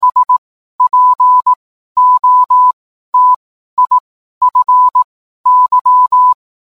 Morse code is available in the attached file.
spotify_morse_code.mp3